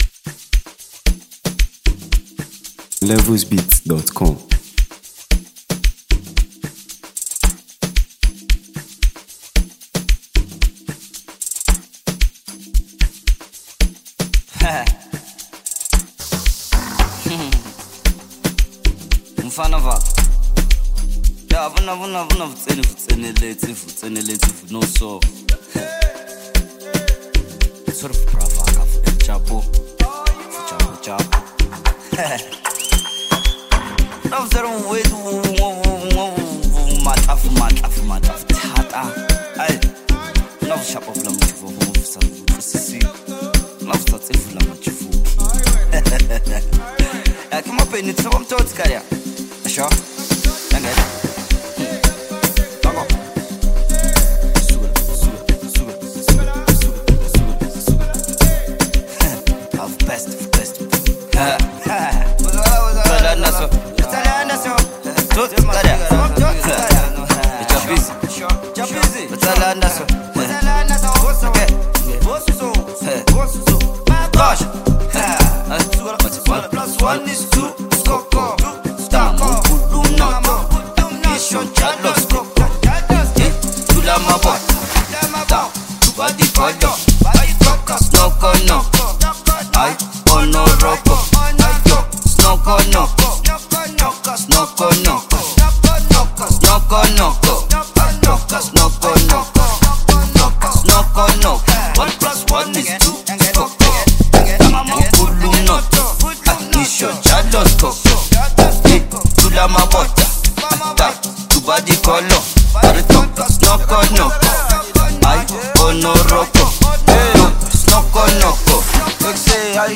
South African Music 2025 6:39